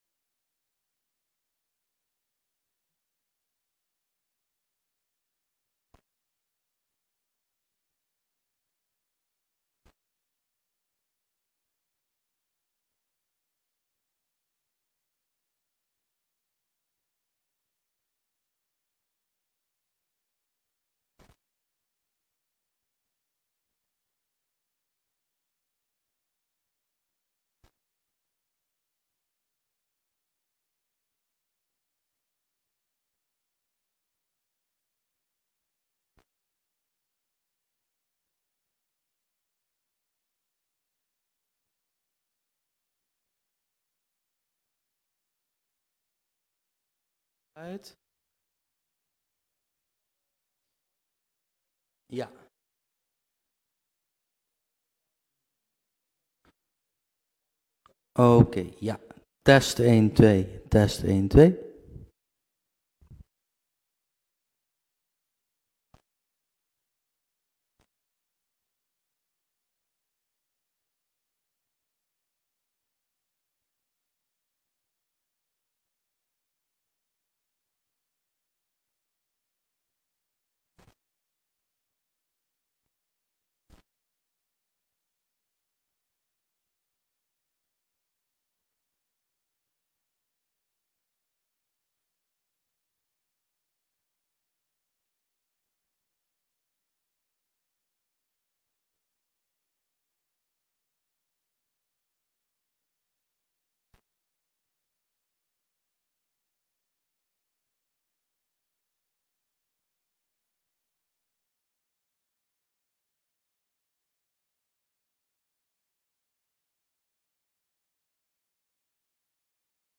Oordeelsvormende raadsbijeenkomst 01 november 2023 19:00:00, Gemeente Venlo
Stadhuis Raadzaal